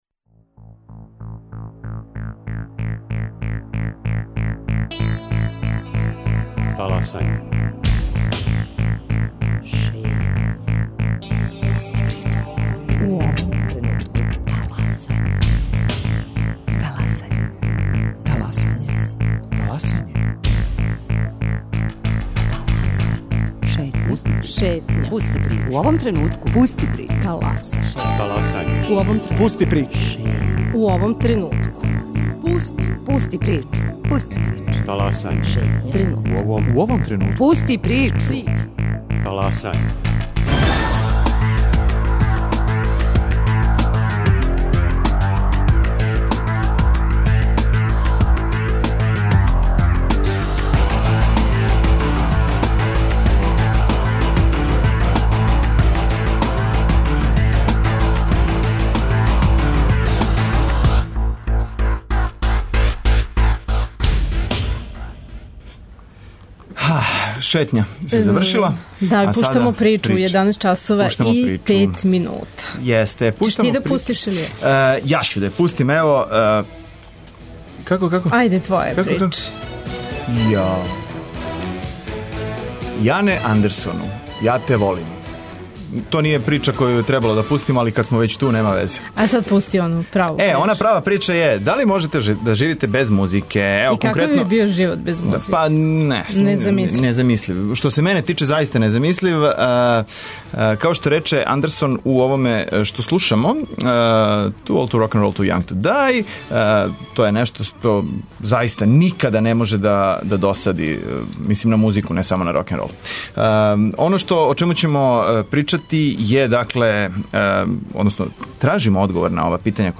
Поводом Светског дана музике, сазнаћемо како је све у Србији обележен тај дан и отворићемо телефоне за све који желе да говоре о значају музике у њиховом животу.